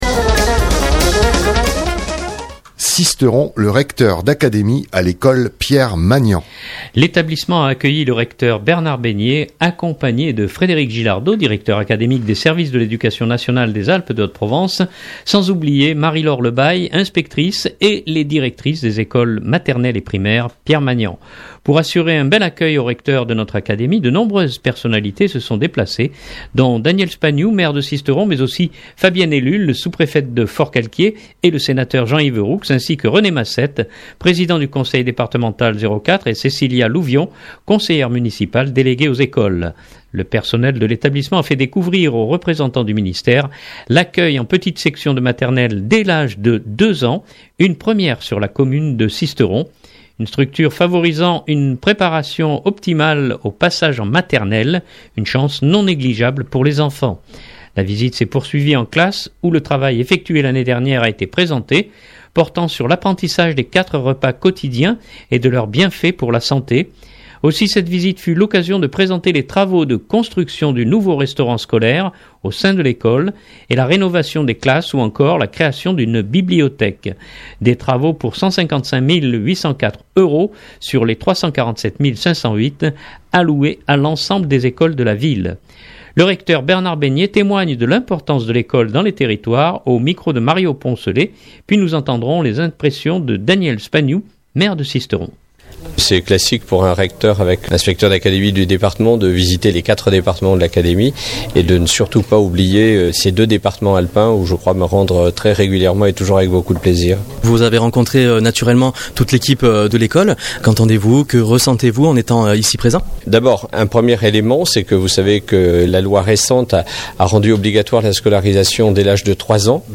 puis nous entendrons les impressions de Daniel Spagnou, maire de Sisteron.